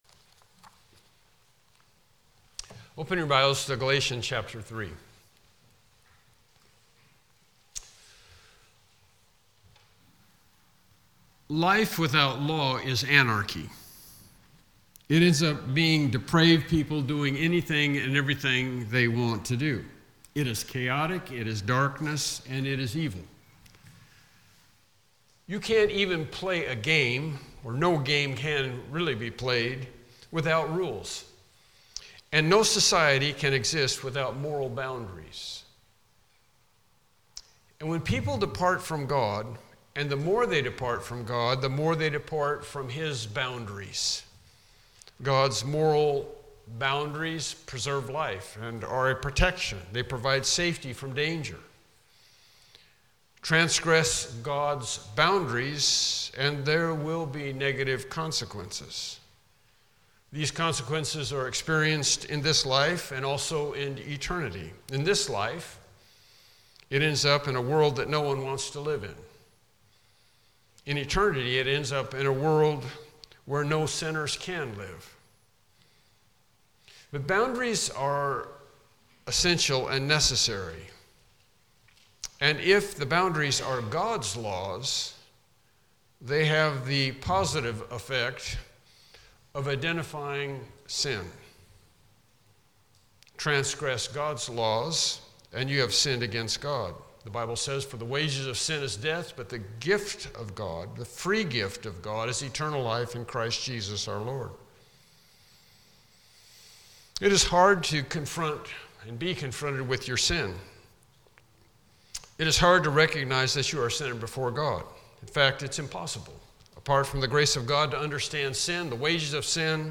Galatians Passage: Galatians 3:20-22 Service Type: Morning Worship Service « Lesson 14